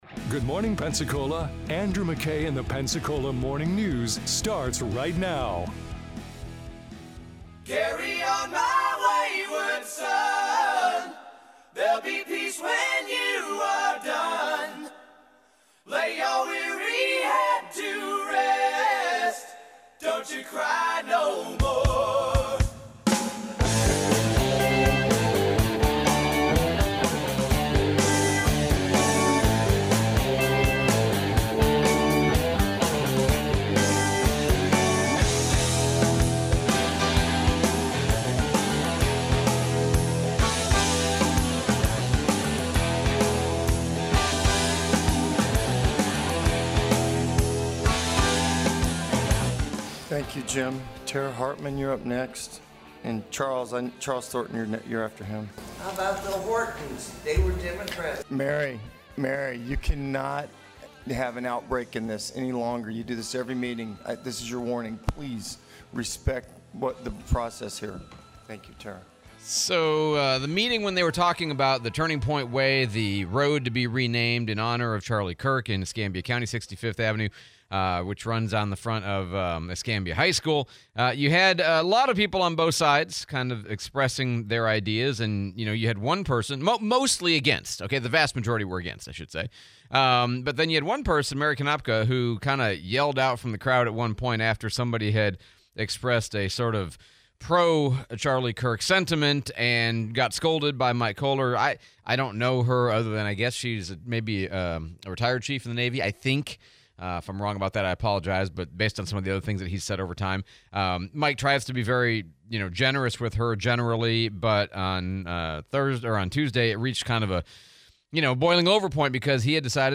Escambia BOCC meeting, Replay of Pensacola Mayor DC Reeves interview